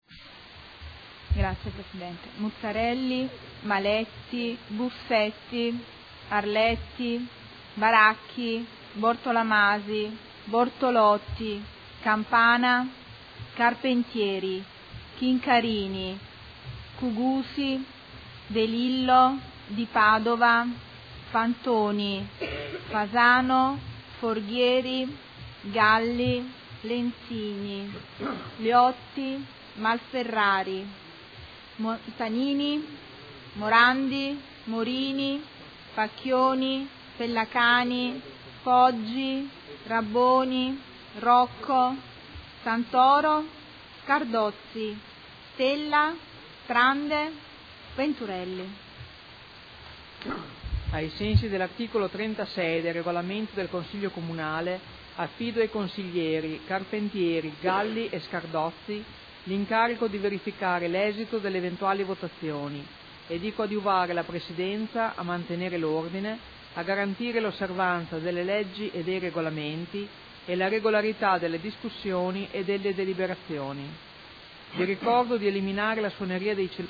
Segretario Generale — Sito Audio Consiglio Comunale
Seduta del 22 ottobre. Appello